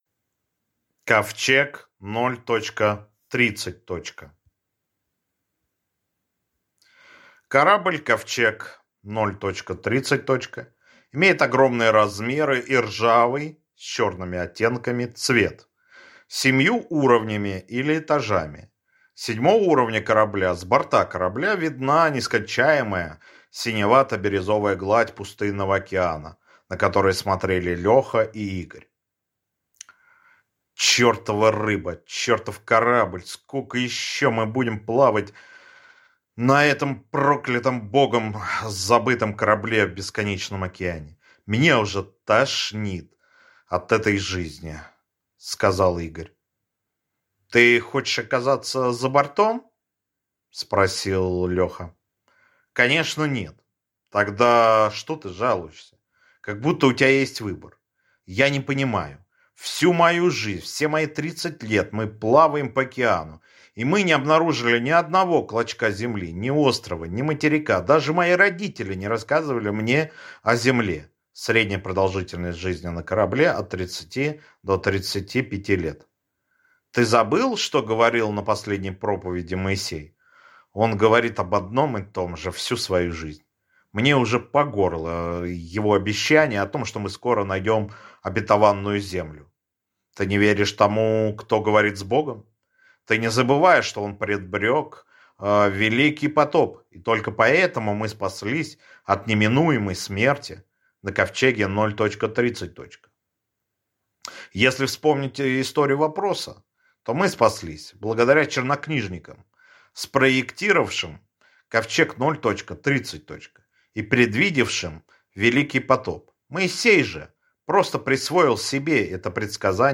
Аудиокнига Ковчег 0.30. | Библиотека аудиокниг